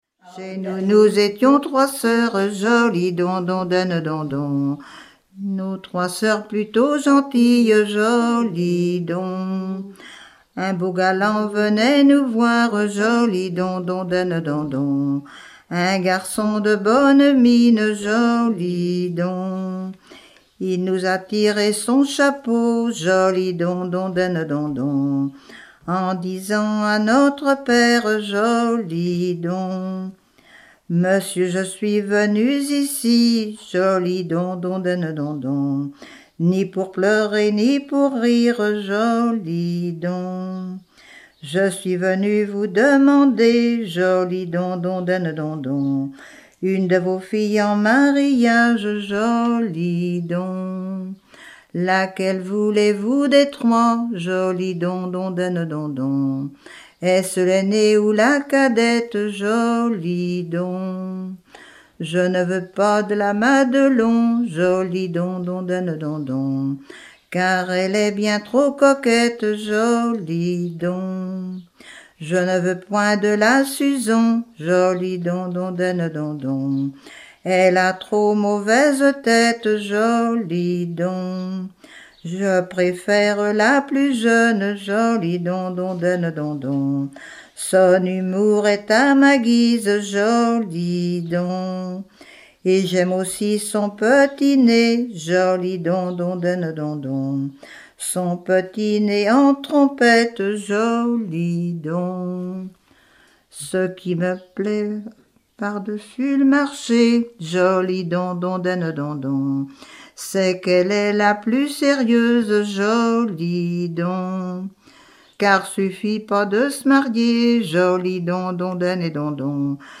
Genre laisse
chanson
Catégorie Pièce musicale inédite